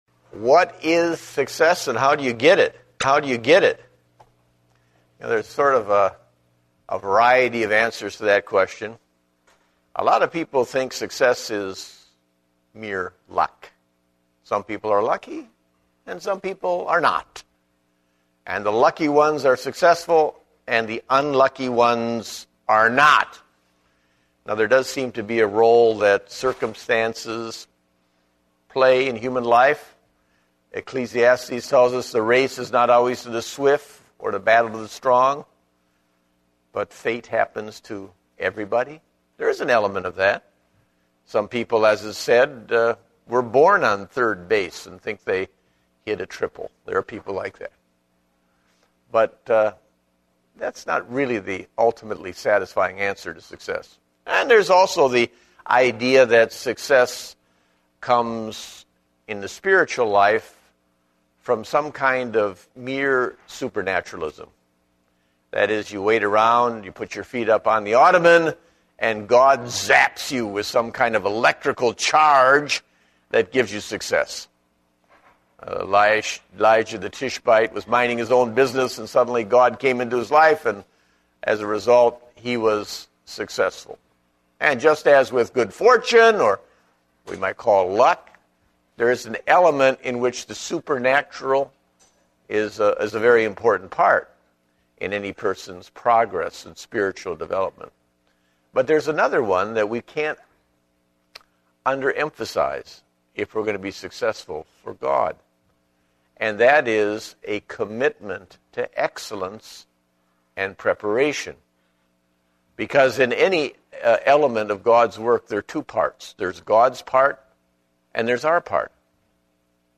Date: September 19, 2010 (Adult Sunday School)